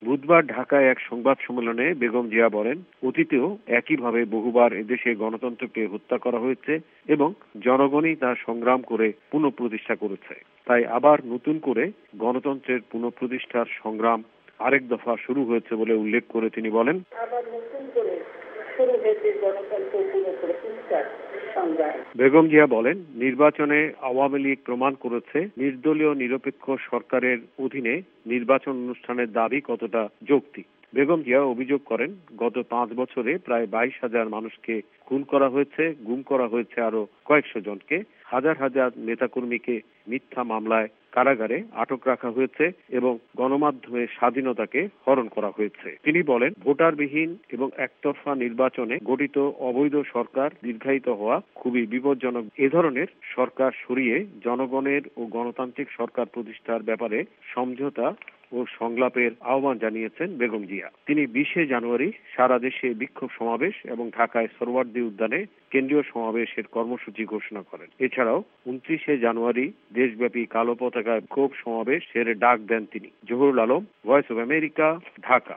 ZIA PRESS CONFERENCE